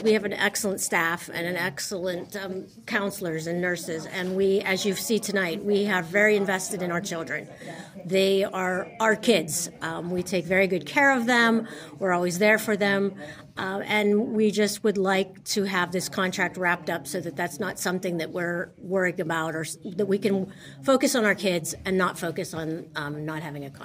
Thursday night, the Homer-Center School Board heard from members of the Homer-Center Education Association about the ongoing contract negotiations.